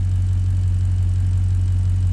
rr3-assets/files/.depot/audio/Vehicles/v8_09/v8_09_idle.wav
v8_09_idle.wav